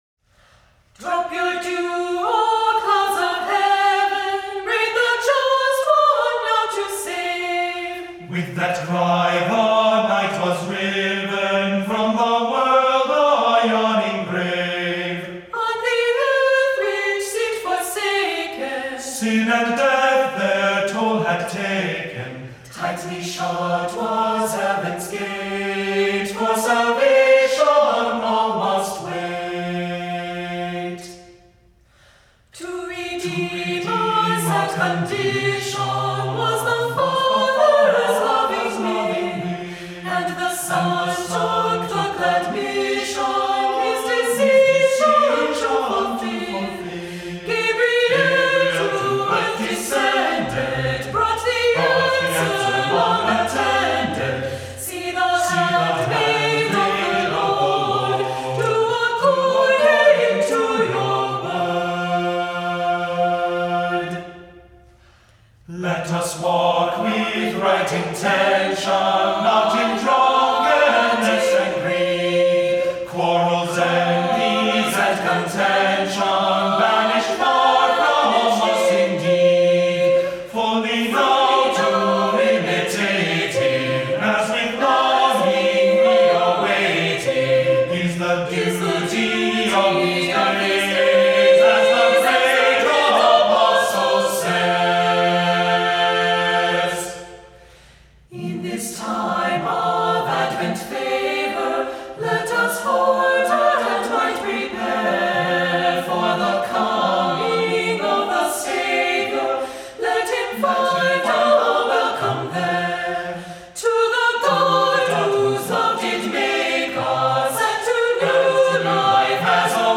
Voicing: "SAB Choir a cappella"